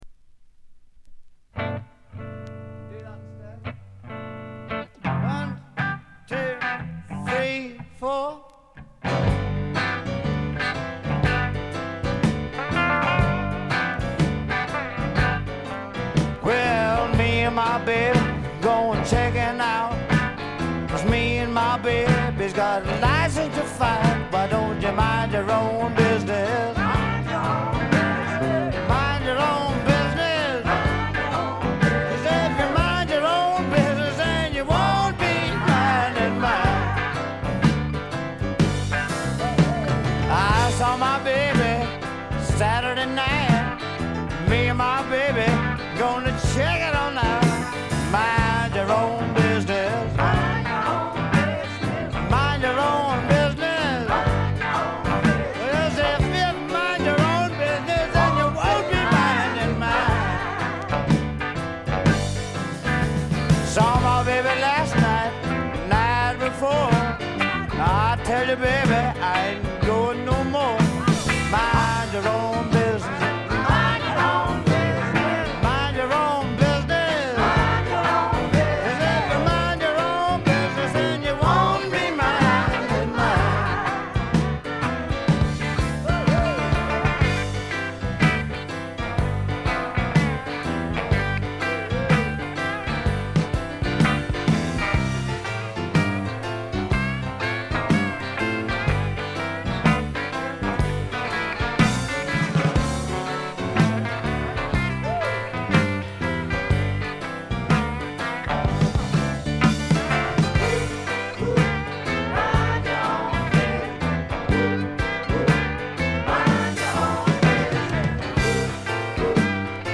微細なノイズ感のみ。
ルーズでちょこっと調子っぱずれなあの愛すべきヴォーカルがまたよくて、本作の雰囲気を盛り上げています。
試聴曲は現品からの取り込み音源です。